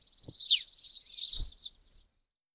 Birds 5.wav